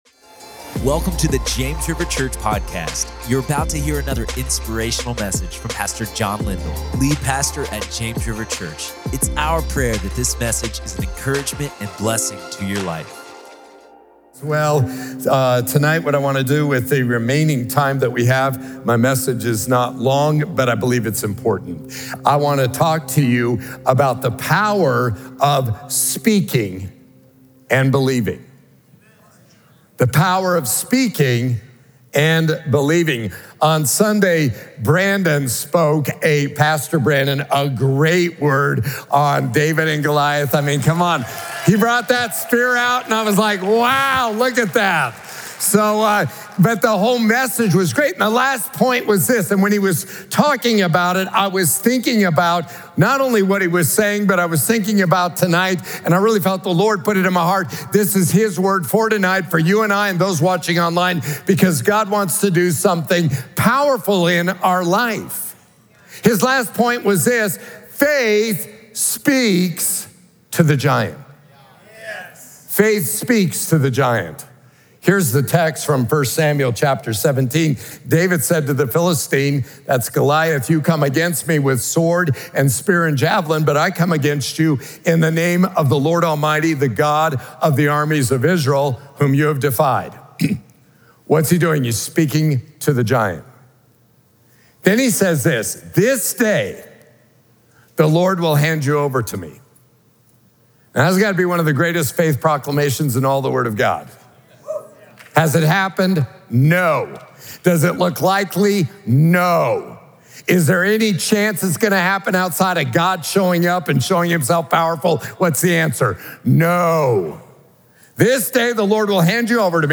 The Power of Speaking & Believing | Prayer Meeting